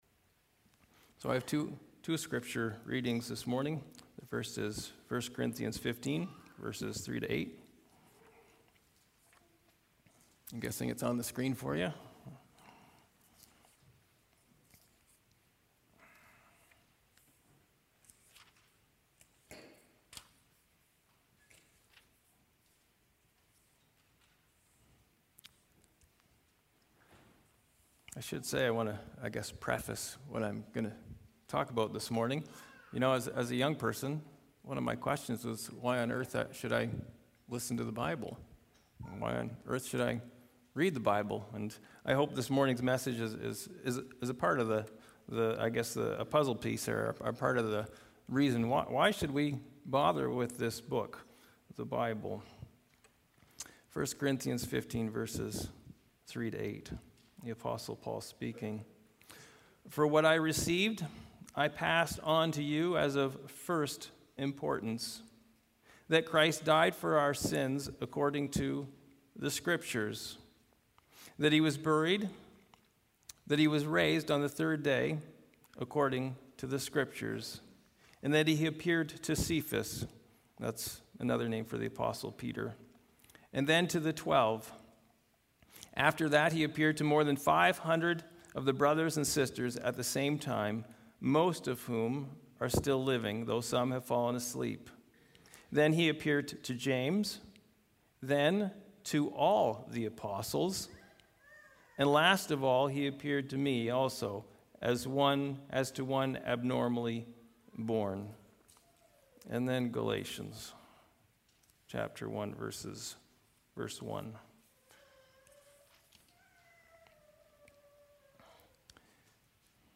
Sermons | Ebenezer Christian Reformed Church